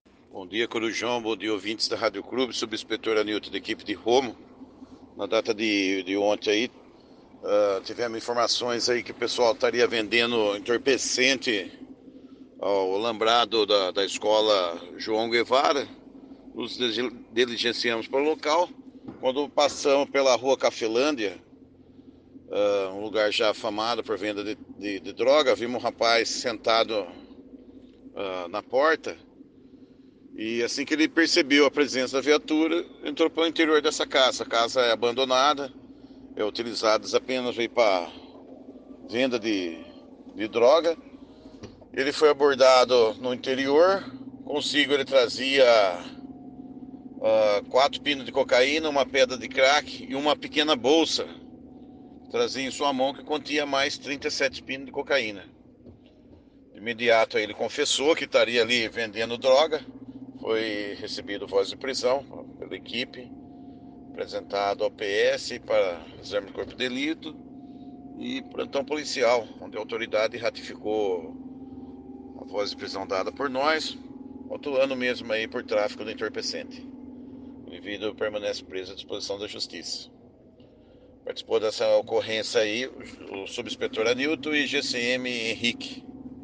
conversou com exclusividade com o